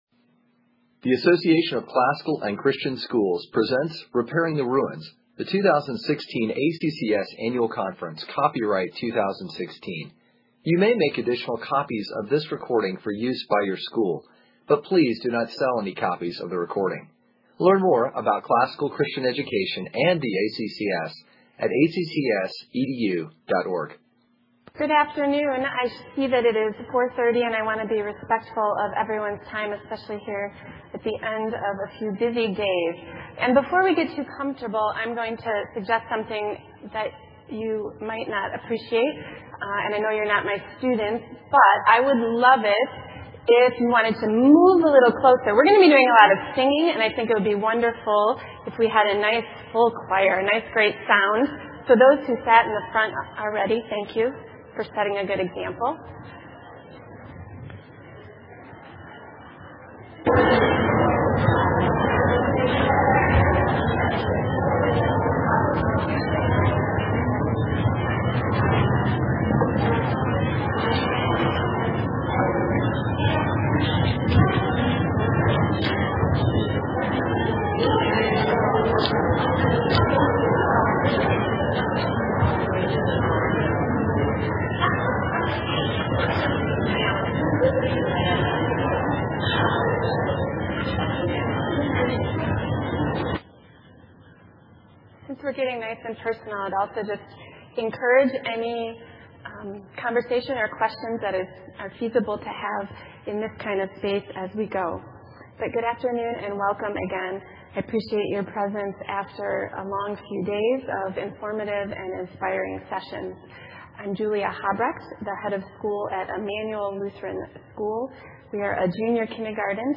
2016 Workshop Talk | 0:59:20 | All Grade Levels, Art & Music
In this session participants will join each other in music making and song as we consider the important and lively elements of a grammar school music program.
Additional Materials The Association of Classical & Christian Schools presents Repairing the Ruins, the ACCS annual conference, copyright ACCS.